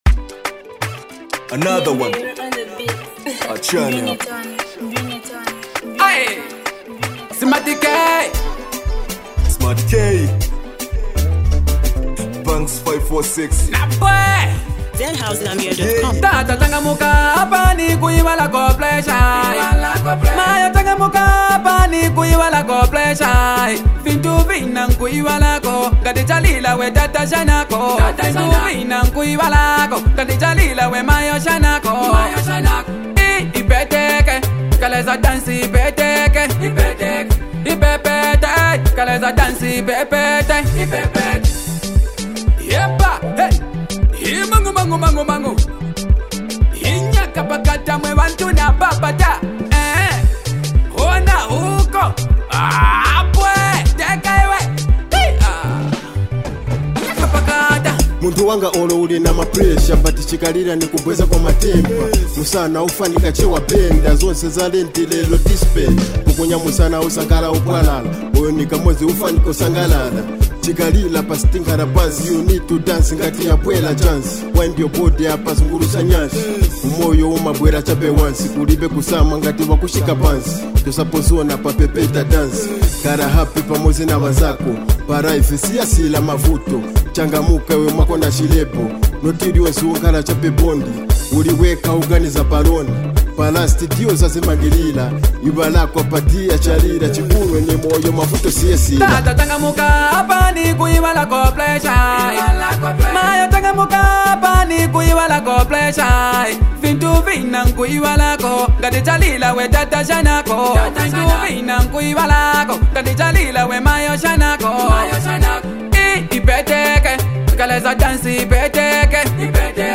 a hard-hitting record